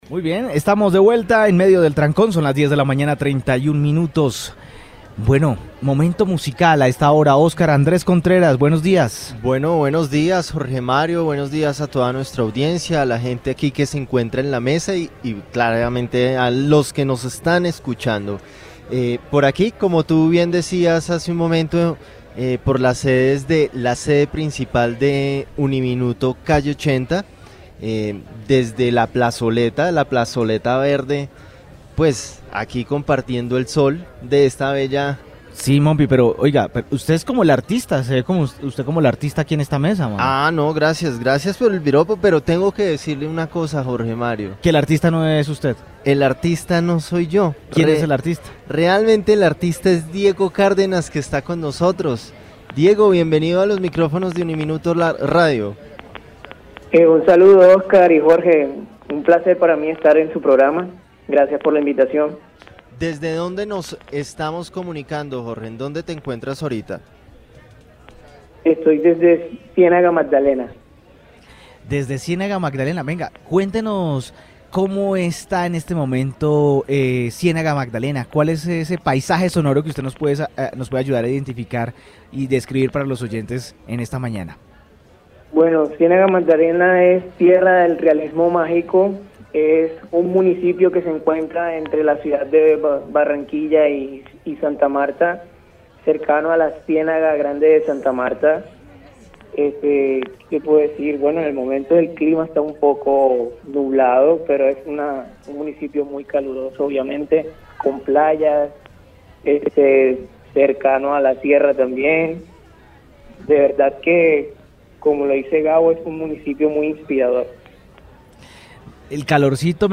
Aunque corta fue la conversación telefónica que atendió, más que suficiente para entender la esencia de este cantautor.